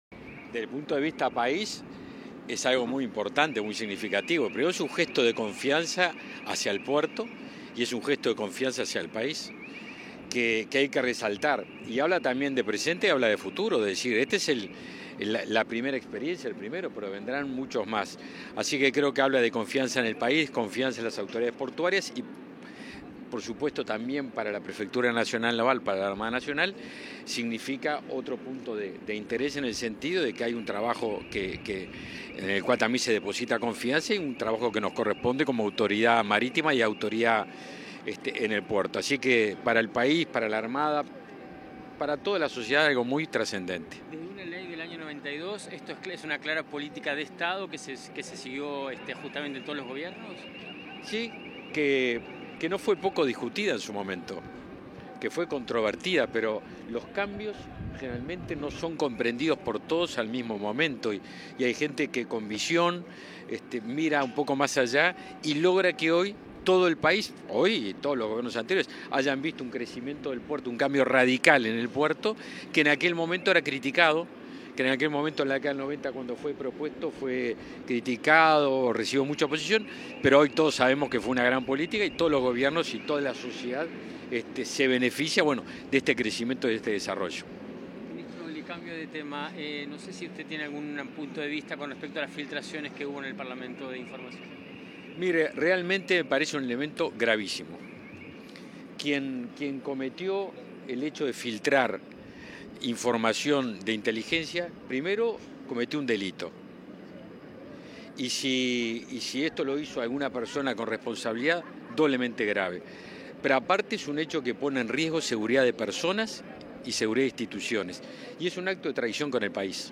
Declaraciones del ministro de Defensa Nacional, Javier García
Declaraciones del ministro de Defensa Nacional, Javier García 03/11/2022 Compartir Facebook X Copiar enlace WhatsApp LinkedIn Tras la llegada al puerto de Montevideo del buque portacontenedores Río de Janeiro Express de la naviera Hapag Lloyd, el ministro de Defensa Nacional, Javier García, realizó declaraciones a la prensa.